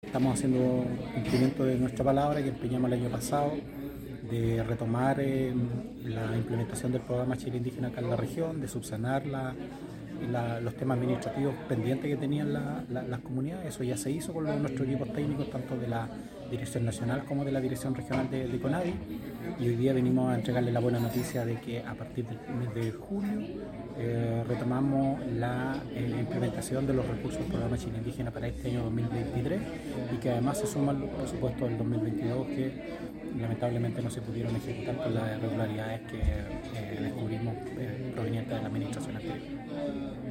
CUNA-DIRECTOR-NACIONAL-CONADI-LUIS_PENCHULEO.mp3